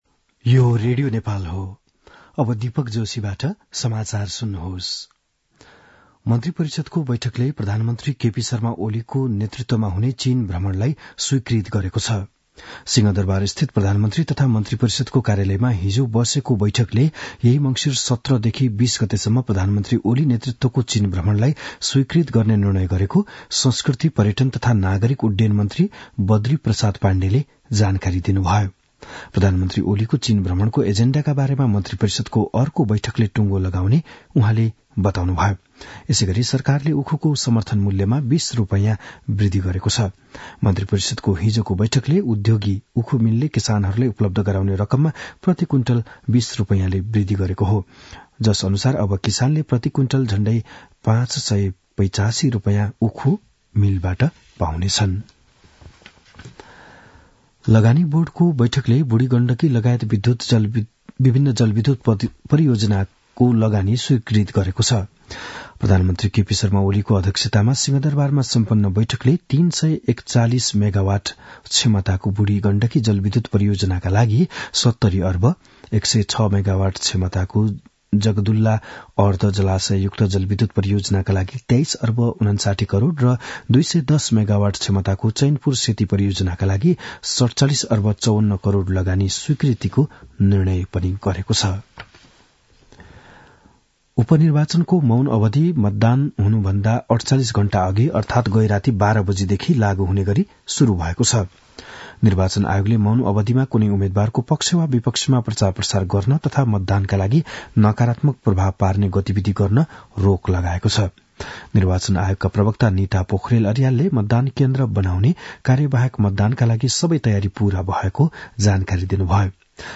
बिहान ११ बजेको नेपाली समाचार : १५ मंसिर , २०८१
11-am-nepali-news-1-12.mp3